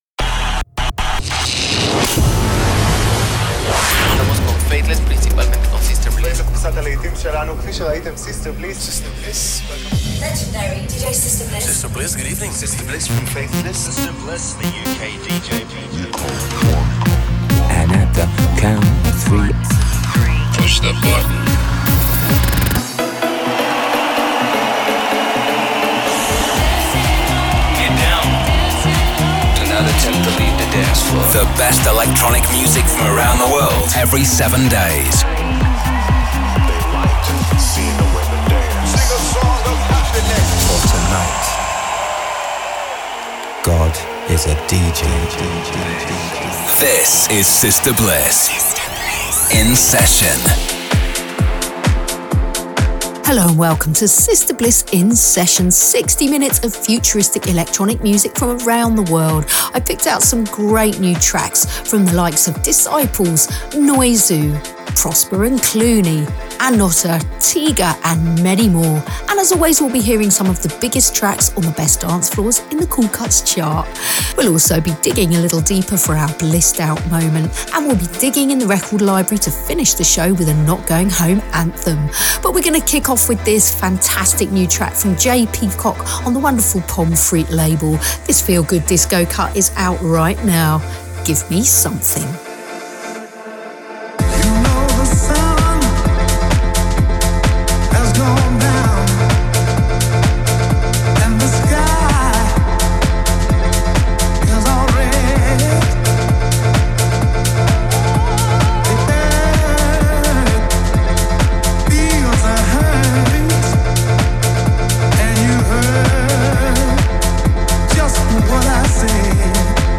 showcases the worlds best new upfront electronic music
a live DJ mix